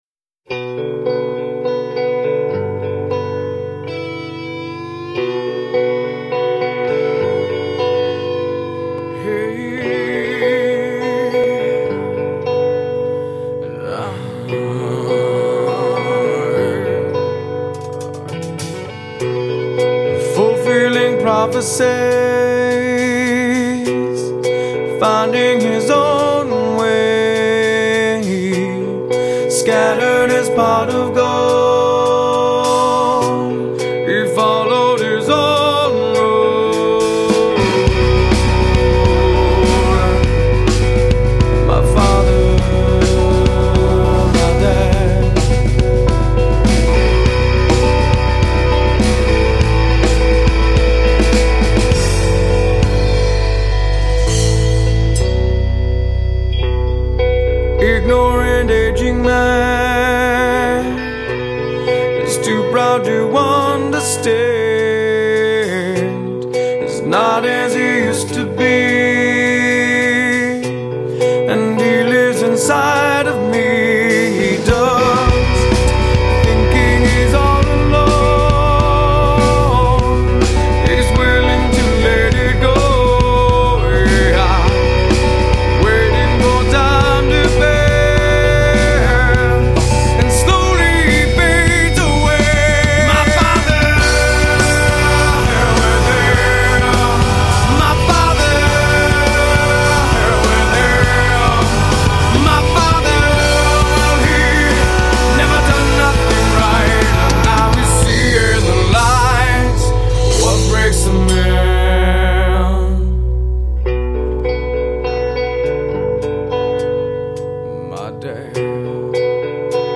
self-produced demo CD: